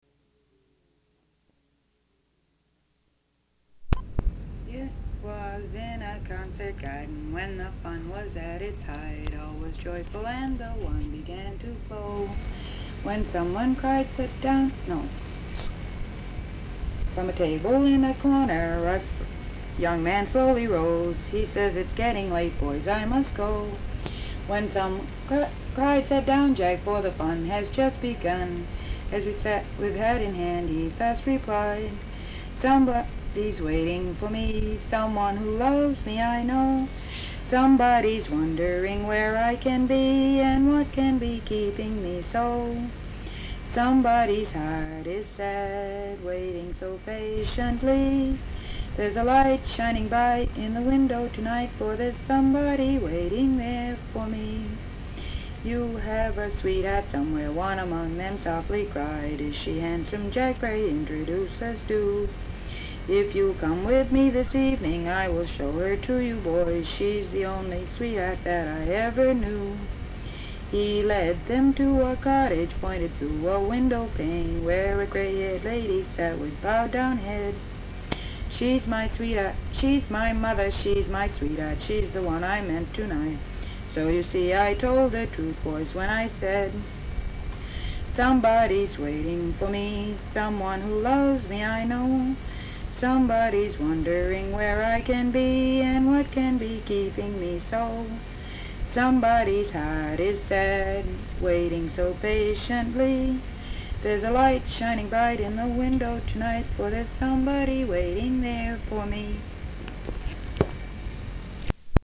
Folk songs, English--Vermont
sound tape reel (analog)
Location Brattleboro, Vermont